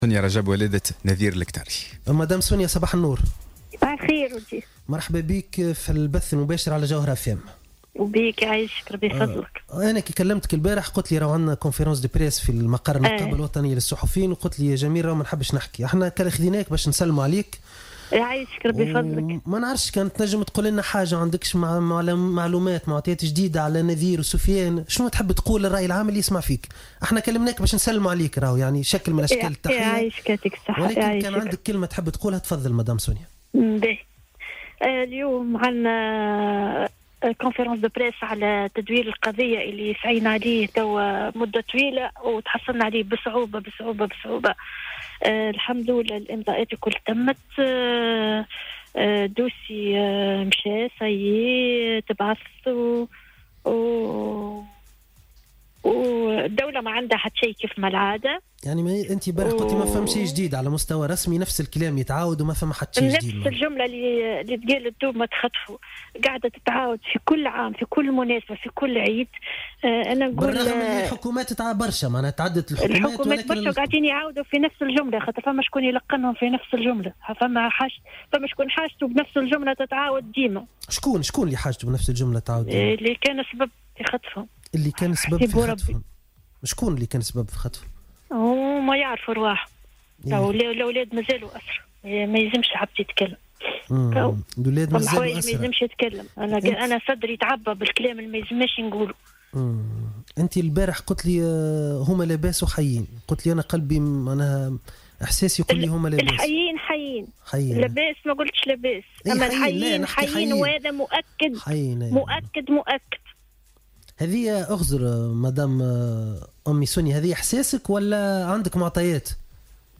Intervenue lors de l'émission matinale de Jawhara FM